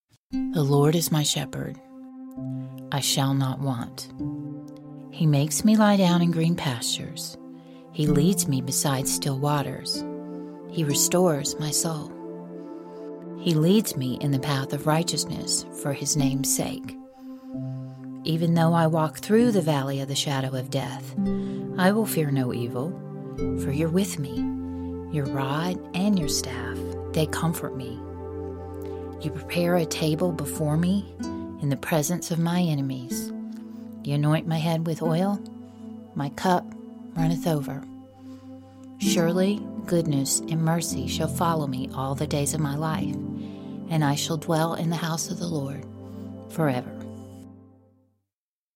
Facebook STORYTELLING & NARRATION Psalm 23 https